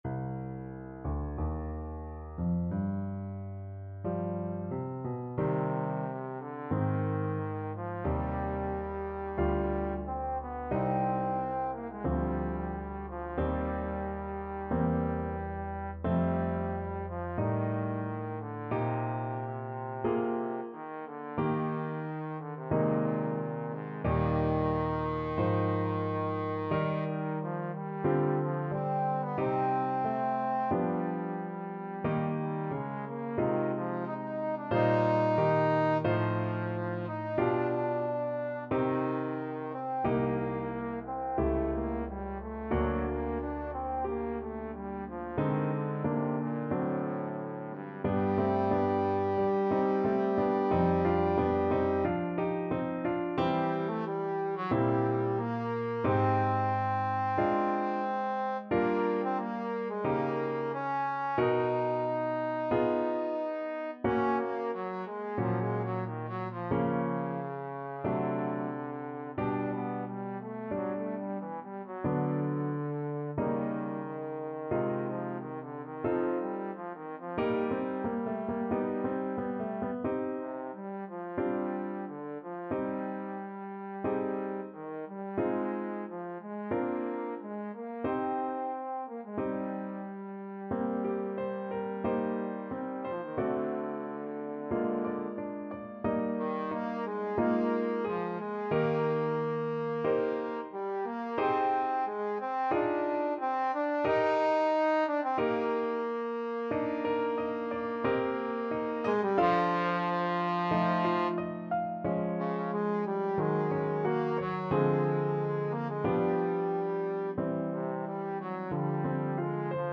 Trombone
C minor (Sounding Pitch) (View more C minor Music for Trombone )
G3-Eb5
4/4 (View more 4/4 Music)
Adagio, molto tranquillo (=60) =45
Classical (View more Classical Trombone Music)